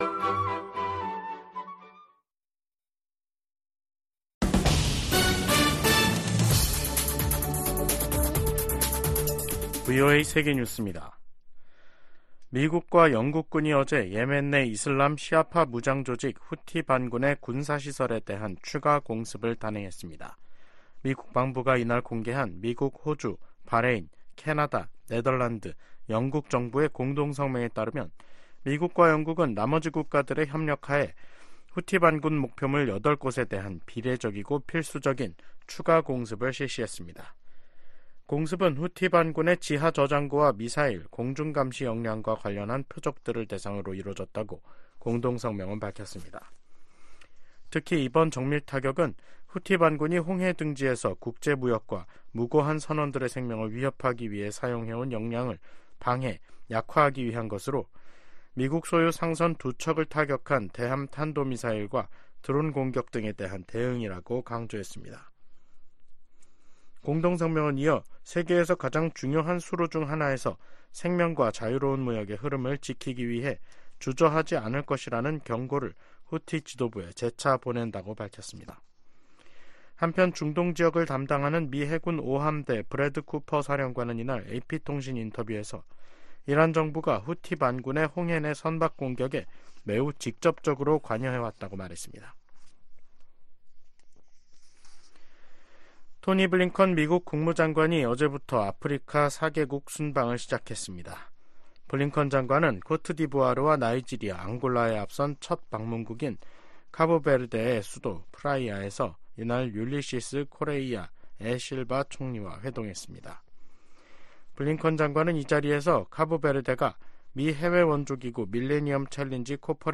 VOA 한국어 간판 뉴스 프로그램 '뉴스 투데이', 2024년 1월 23일 2부 방송입니다. 북한-러시아 군사협력은 역내 안정과 국제 비확산 체제를 약화시킨다고 유엔 주재 미국 차석대사가 지적했습니다. 백악관이 북한과 러시아의 무기 거래를 거론하며 우크라이나에 대한 지원의 필요성을 강조했습니다. 중국에 대한 보편적 정례 인권 검토(UPR)를 앞두고 유엔과 유럽연합(EU)이 탈북민 강제 북송 중단을 중국에 촉구했습니다.